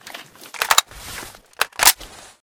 akm_reload.ogg